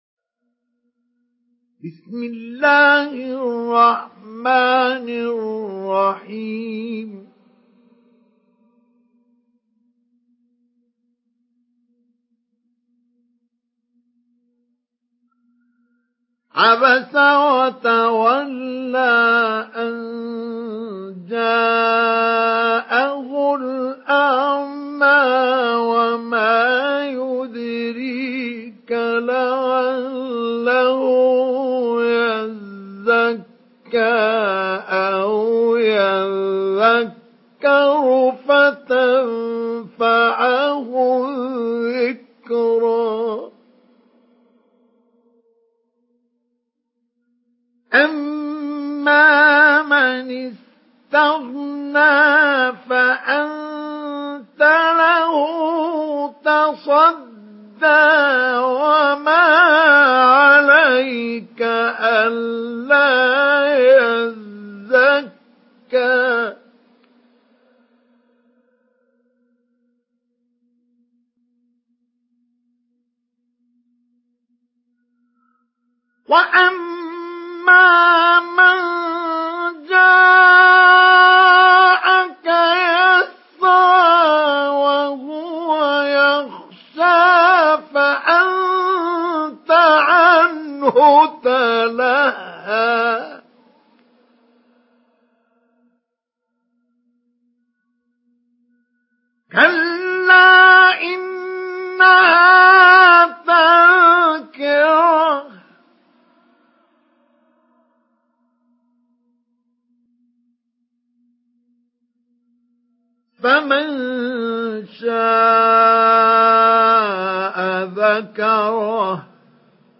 Surah ‘আবাসা MP3 in the Voice of Mustafa Ismail Mujawwad in Hafs Narration
Surah ‘আবাসা MP3 by Mustafa Ismail Mujawwad in Hafs An Asim narration. Listen and download the full recitation in MP3 format via direct and fast links in multiple qualities to your mobile phone.